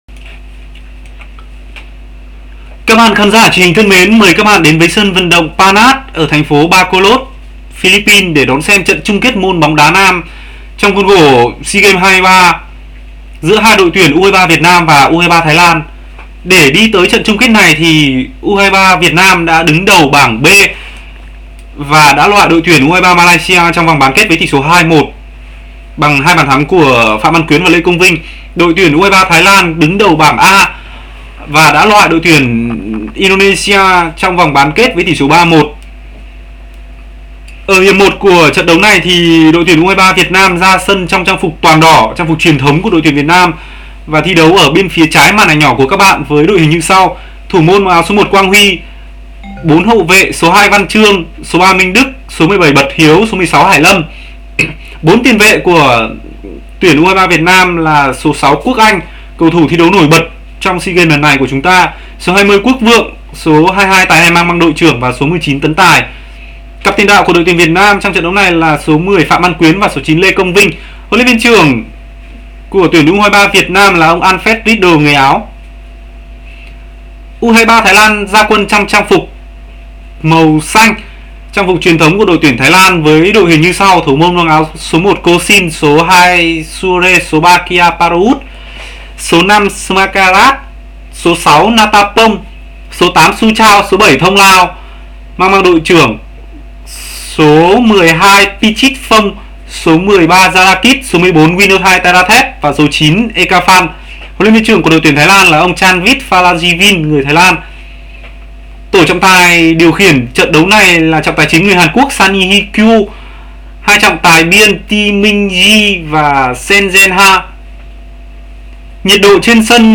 cổ vũ tinh thần bóng đá của tuyển Việt nam, chúng tôi gửi tới các bạn link tường thuật trực tiếp trận chung kết Seagames 23.